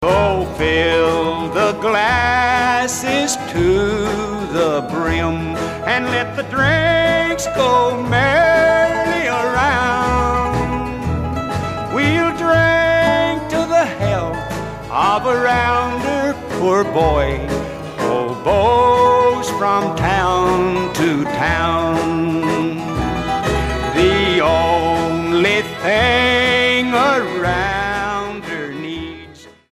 STYLE: Country
Now, with the reissue of these two Hickory albums from the early '60s we've got a chance to hear that hauntingly plaintive mountain style recorded in its prime.